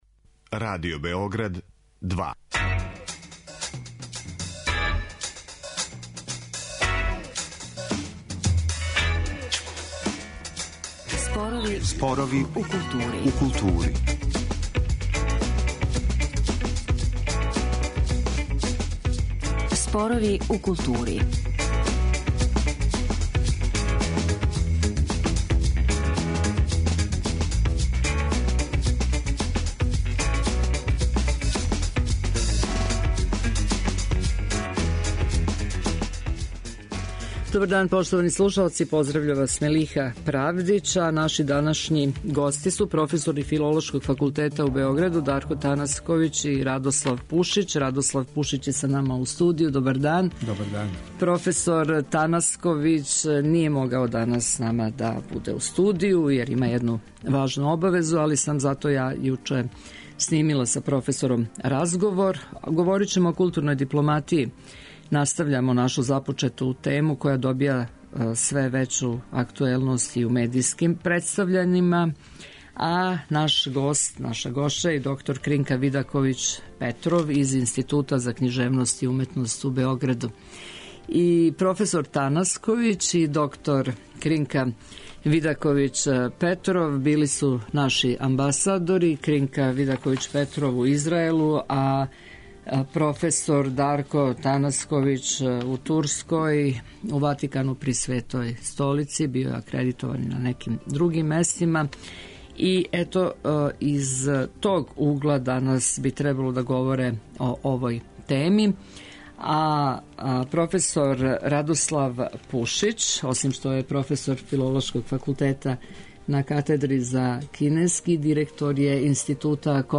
Разговор је снимљен 30.5.2014. године.